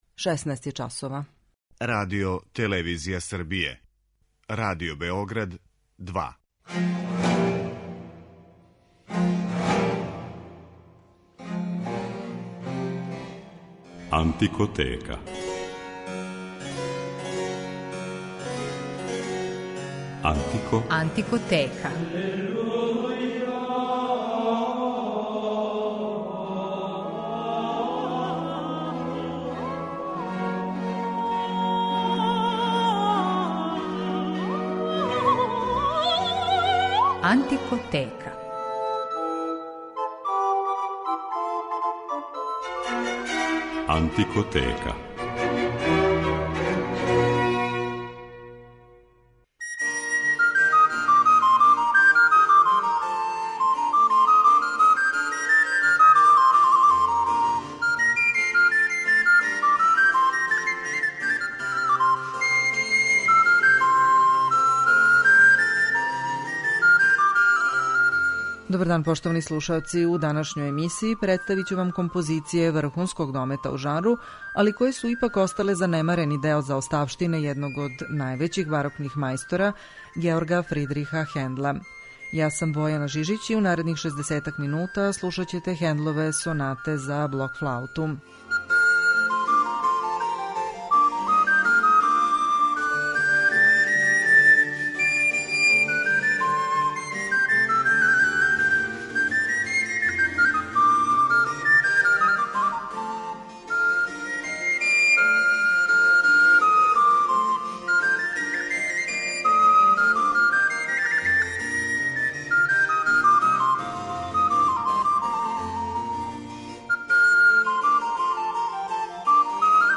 Хендлове сонате за блок флауту